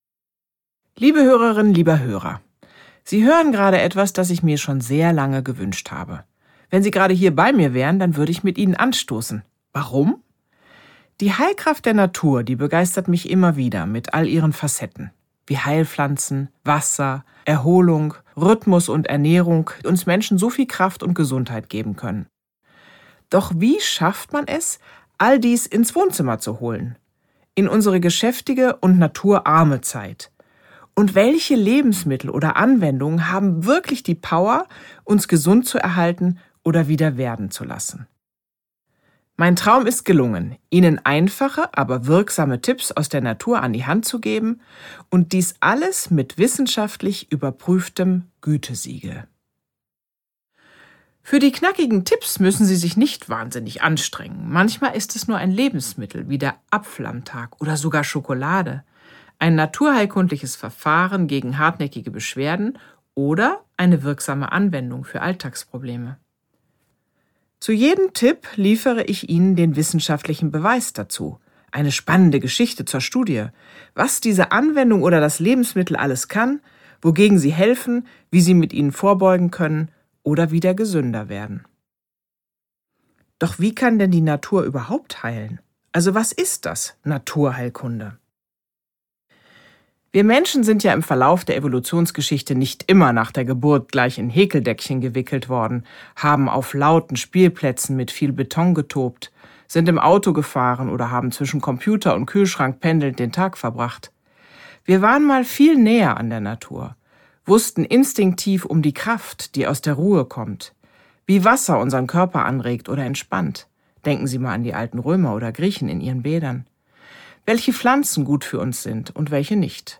Einfach heilen mit Natur! Die wirksamsten Heilmittel – wissenschaftlich belegt Franziska Rubin (Autor) Franziska Rubin (Sprecher) Audio-CD 2022 | 1.